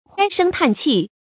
咳声叹气 hāi shēng tàn qì
咳声叹气发音
成语注音 ㄏㄞ ㄕㄥ ㄊㄢˋ ㄑㄧˋ